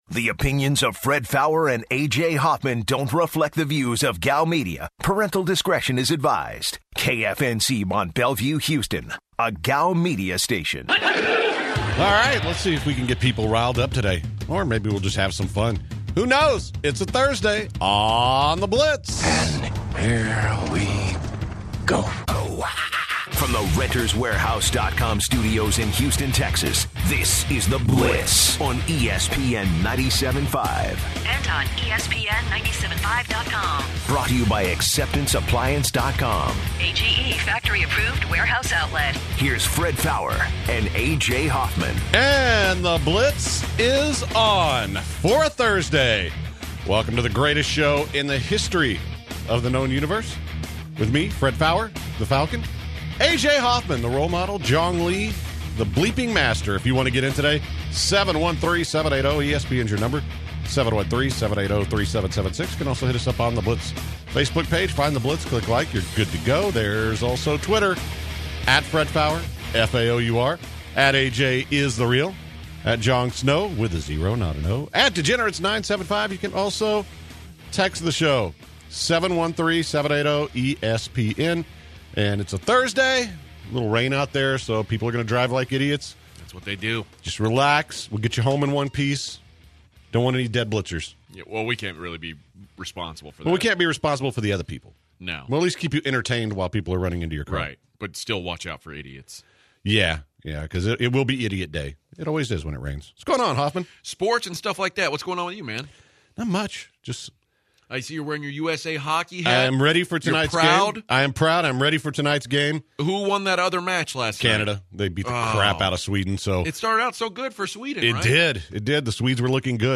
To begin the hour they go over the slate of games in the playoffs and they talk about the QB situation on both Oakland and Houston. They also have UFC fighters Dennis Bermudez and Alexa Grasso in studio to talk about their fight at the Toyota center.